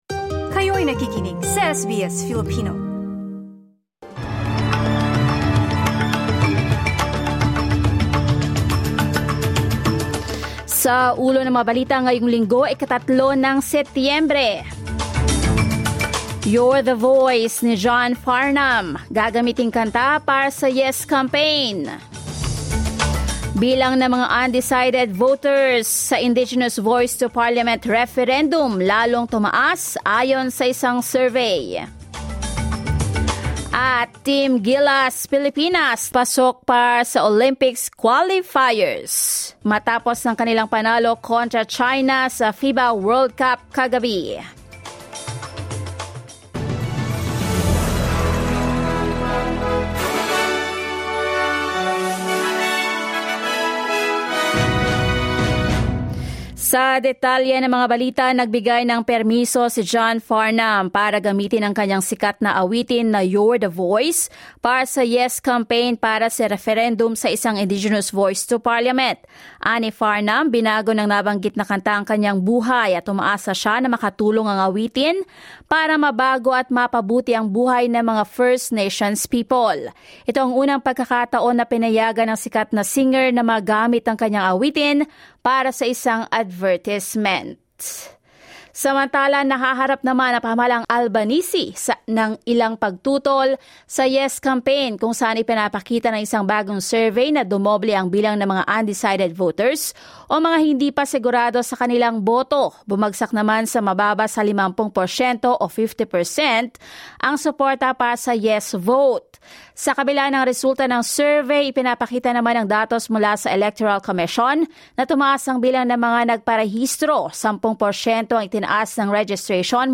SBS News in Filipino, Sunday 3 September